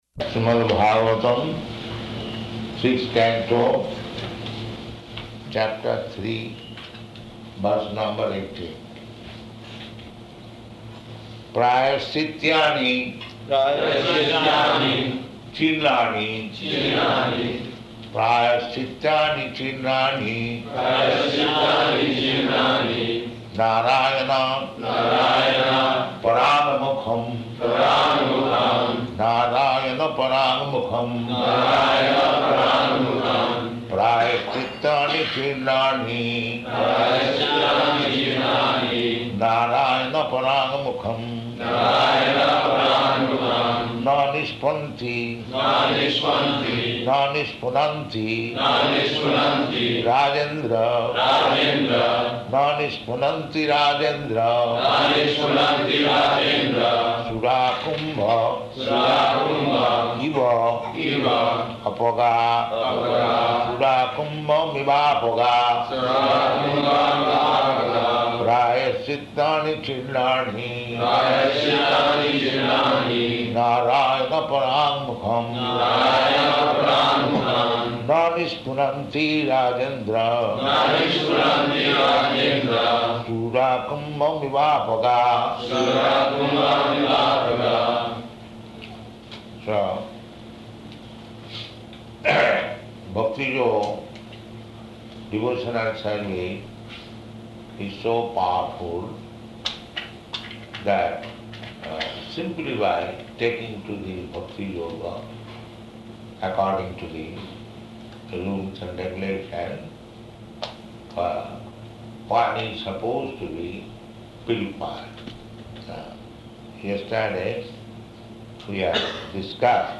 Śrīmad-Bhāgavatam 6.1.18 --:-- --:-- Type: Srimad-Bhagavatam Dated: May 18th 1976 Location: Honolulu Audio file: 760518SB.HON.mp3 Prabhupāda: Śrīmad-Bhāgavatam, Sixth Canto, Chapter Three, verse number eighteen. [leads devotees in chanting]